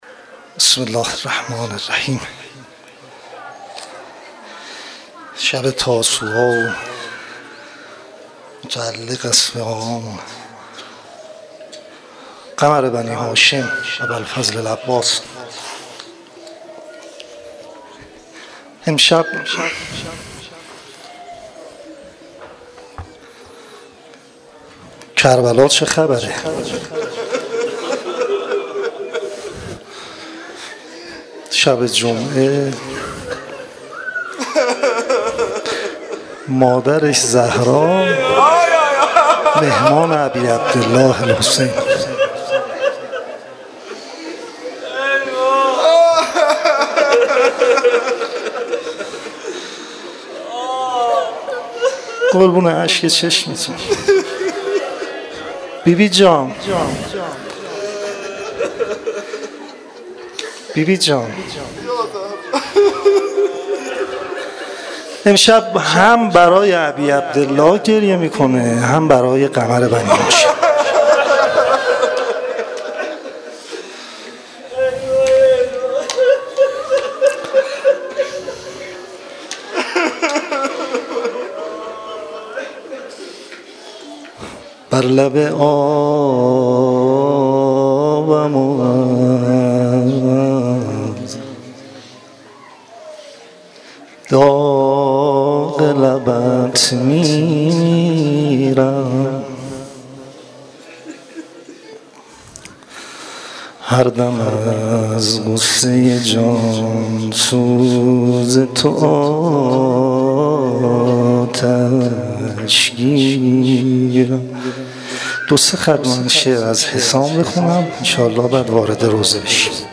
روضه شب نهم محرم